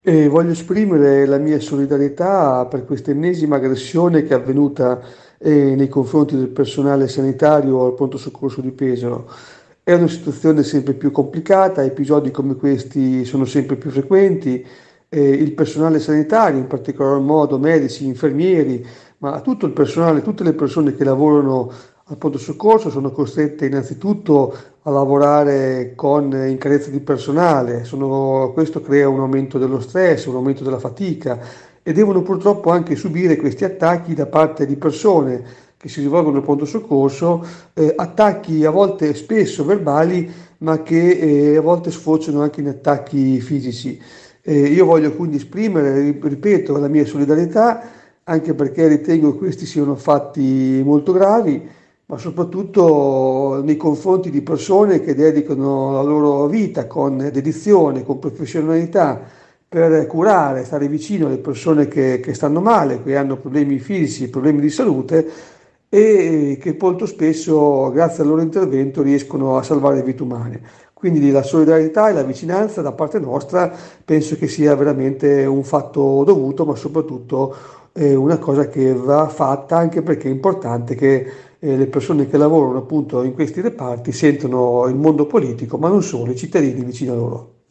Ai nostri microfoni, il suo intervento.
Biancani-aggressione-sanitari.mp3